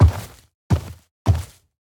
Minecraft Version Minecraft Version snapshot Latest Release | Latest Snapshot snapshot / assets / minecraft / sounds / mob / sniffer / step4.ogg Compare With Compare With Latest Release | Latest Snapshot